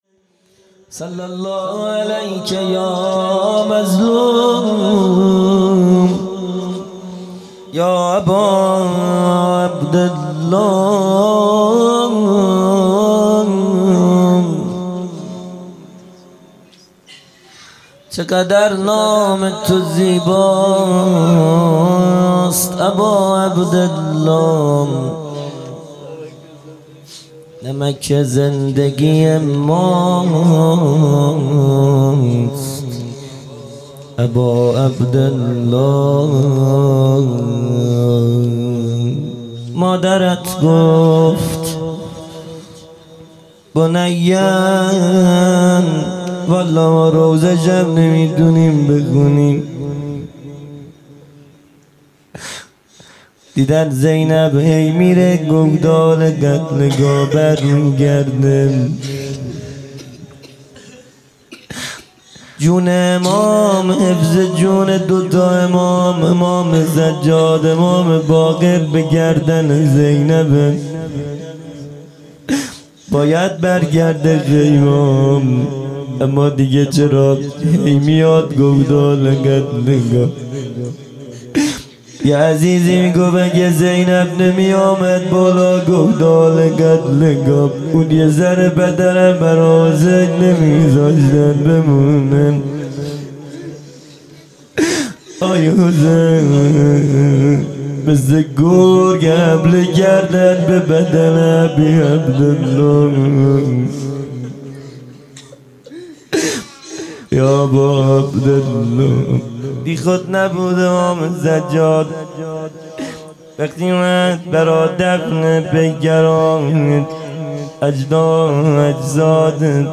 0 0 مناجات پایانی
جلسۀ هفتگی